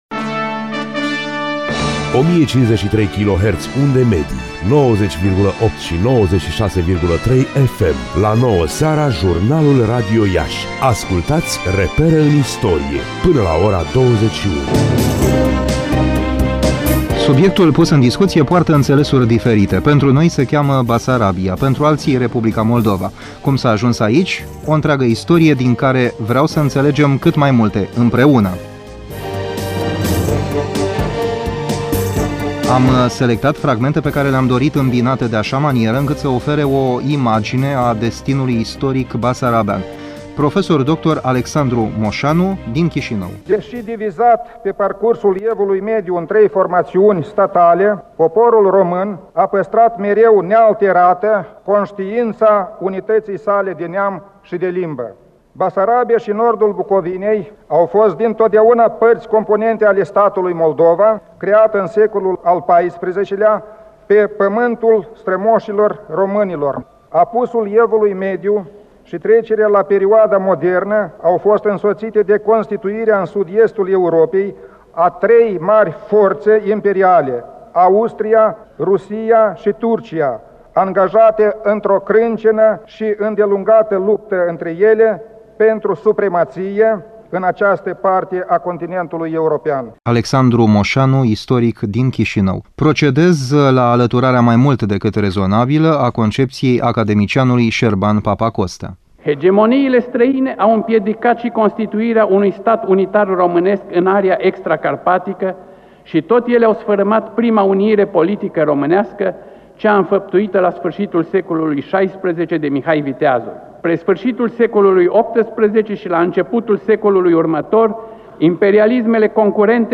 emisiune difuzată pe frecvențele Radio Iași/2012